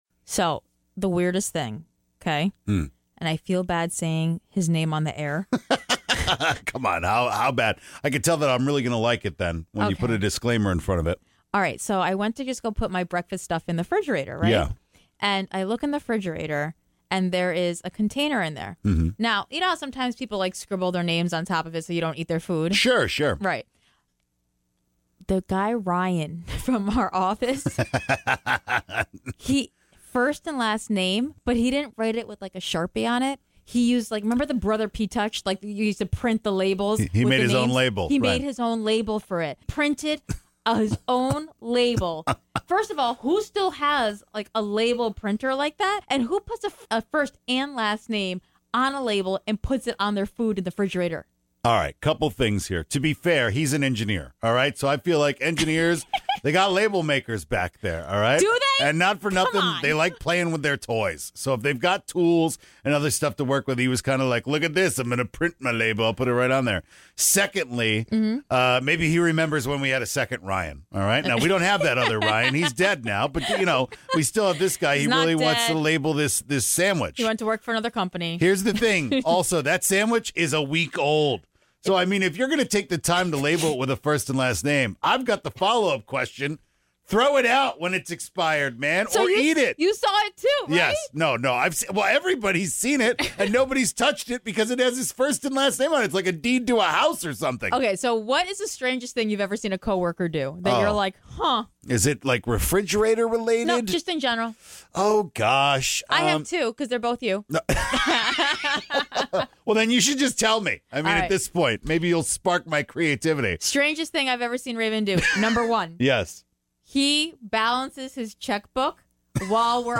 But one caller fell asleep somewhere that almost got her locked up in a foreign country!